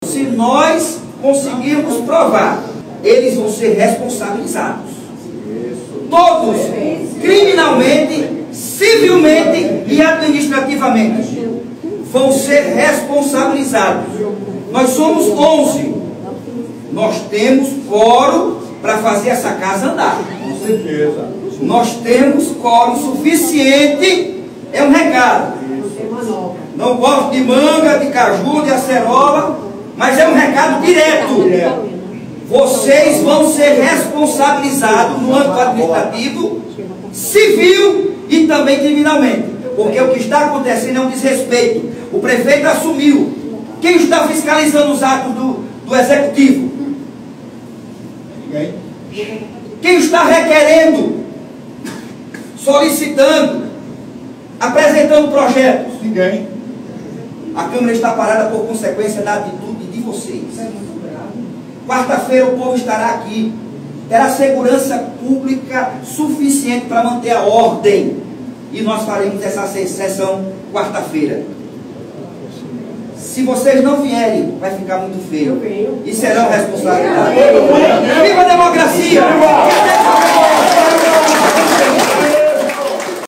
Os comentários do vereador foram registrados pelo programa Correio Debate, da 98 FM, de João Pessoa, nesta segunda-feira (06/01).